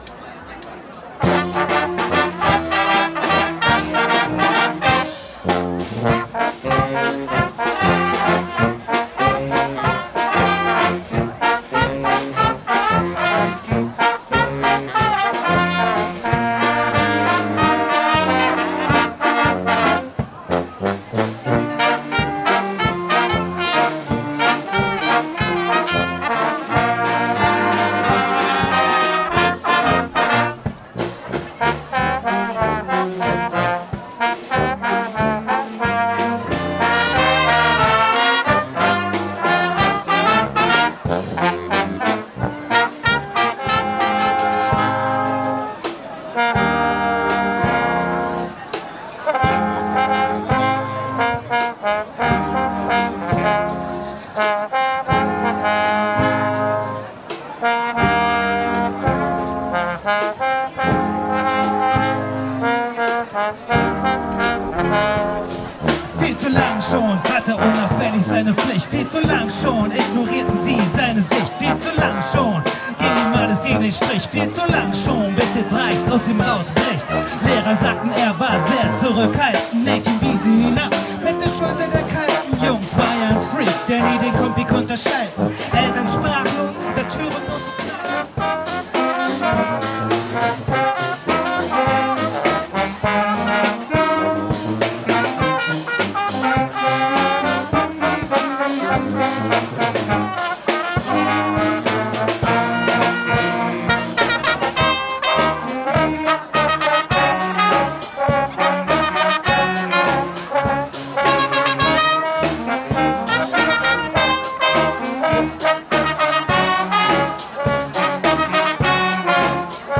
– Die Gäste fun brass cologne
Hörprobe (Mitschnitt Auftritt in Straberg)
Winfest2025-live-Mitschnitt.mp3